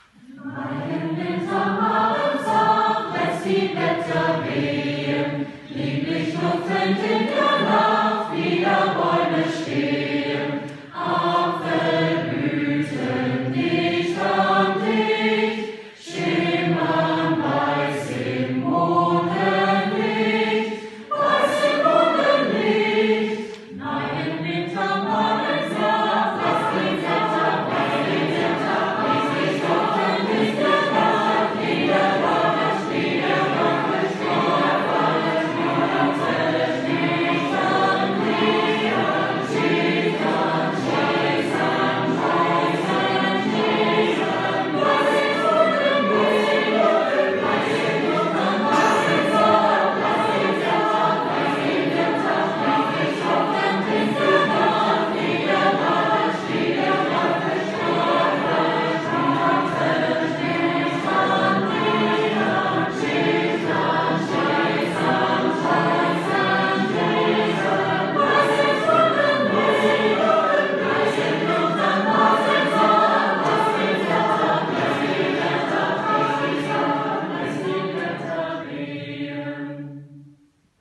Hörbeispiel Ensembleleitung
Ensembleleitung.mp3